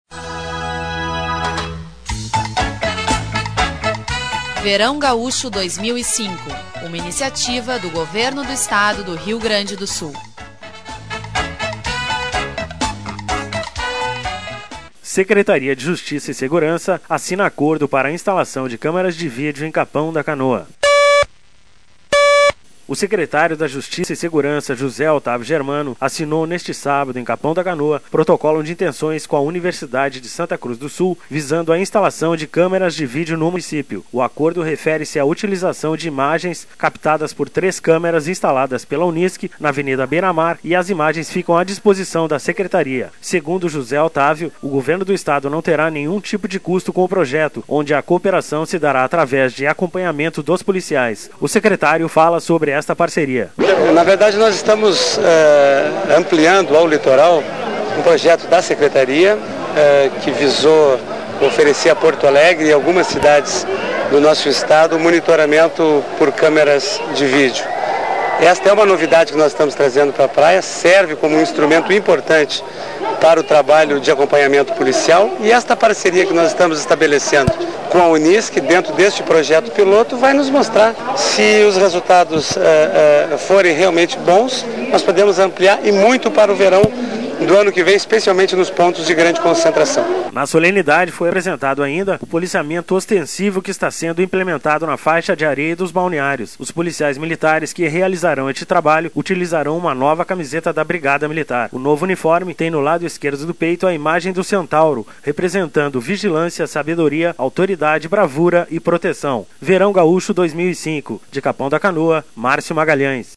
O secretário da Justiça e Segurança, José Otávio Germano, assinou neste sábado, em Capão da Canoa, protocolo de intenções com a Universidade de Santa Cruz do Sul visando a instalação de câmeras de vídeo no município. Sonora: Secretário de Justiça e Seg